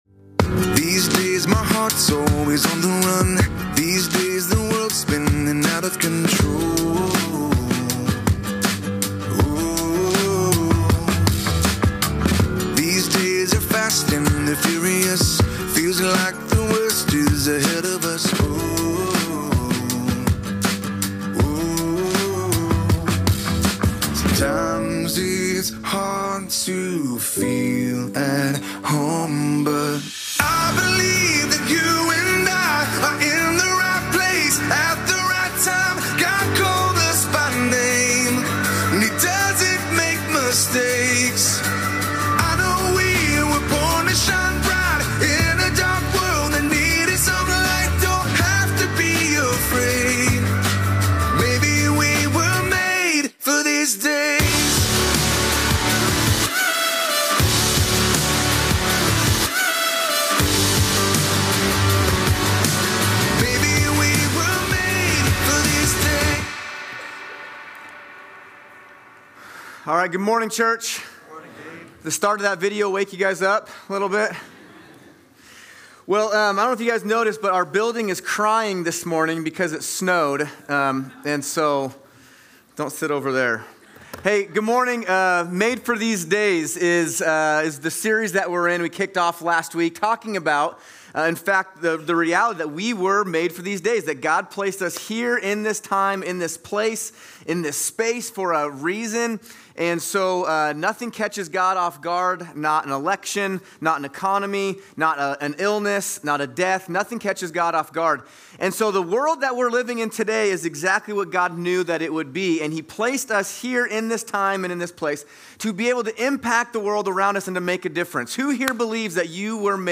11.17.24+Sermon.mp3